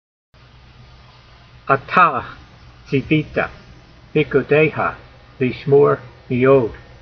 For instance, you hear in the “ee” sound in the first syllable “tsee”.
v4_voice.mp3